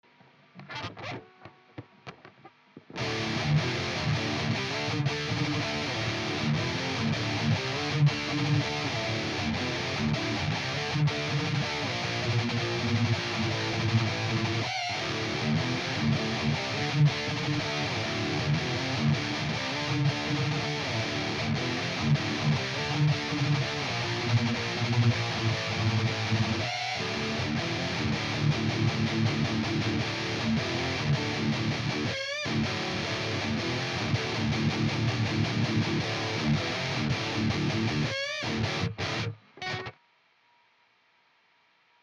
DI-chain: Jackson Dan Dimas (oldschool beauty ;) ) with EMG81 (one of the very early ones) into Little Labs Redeye into API